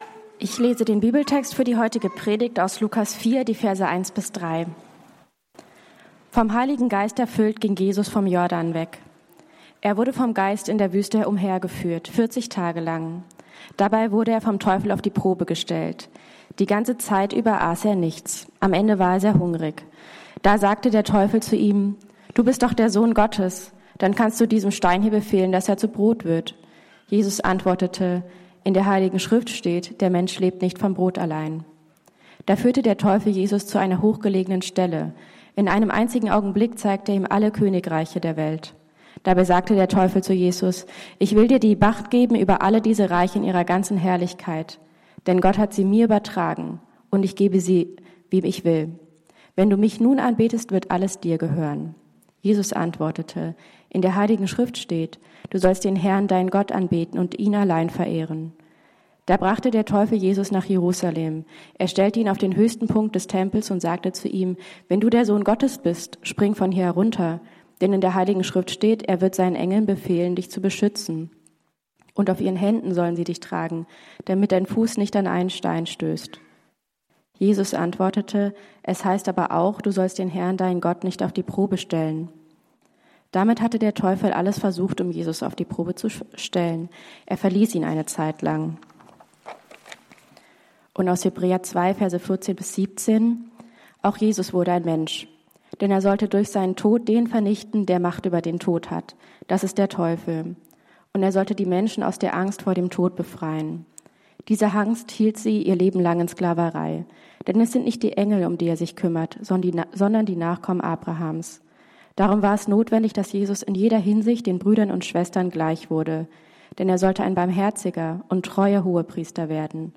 Vertrauen, das durch Kämpfe trägt ~ Berlinprojekt Predigten Podcast